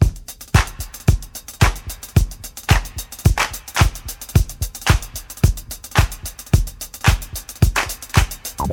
110 Bpm Drum Groove F Key.wav
Free drum loop sample - kick tuned to the F note. Loudest frequency: 2039Hz
110-bpm-drum-groove-f-key-uS2.ogg